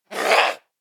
DayZ-Epoch/SQF/dayz_sfx/zombie/spotted_3.ogg at 188825ebc08b75de64682a2209c36de1ba388af0